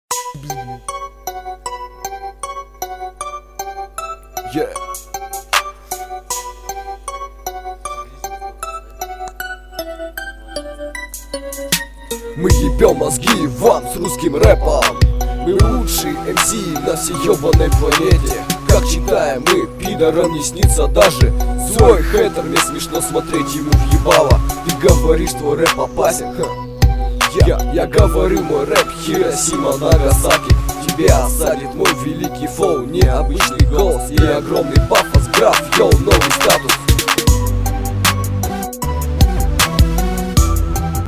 Форум » РЭПЧИК » ВАШИ ТРЕКИ » короче слушайте (???)
это демки
мне_нравиться_тока_у_тя_какойто_акцент_украинский...Ты_с_России???